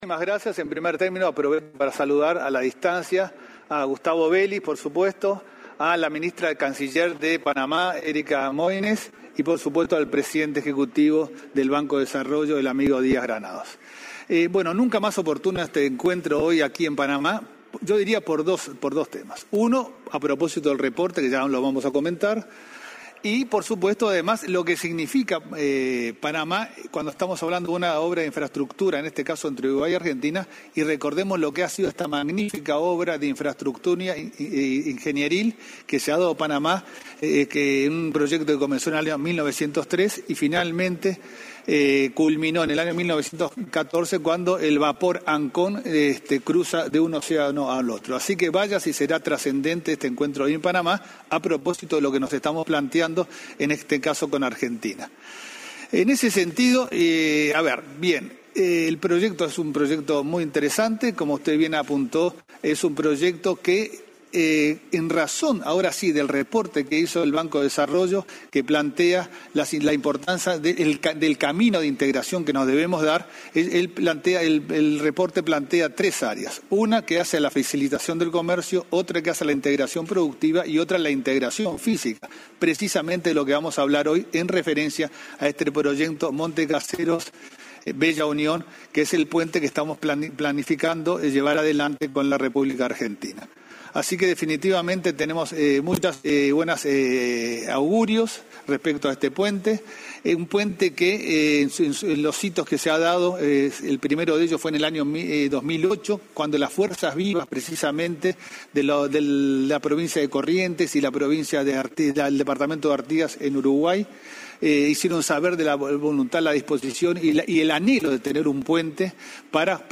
Conferencia de prensa por el lanzamiento regional del Reporte de Economía y Desarrollo 2021 organizado por la CAF
el canciller de Uruguay, Francisco Bustillo, junto al secretario de Asuntos Estratégicos de Argentina, Gustavo Beliz, expusieron acerca de los avances para construir un puente entre las ciudades de Bella Unión y Monte Caseros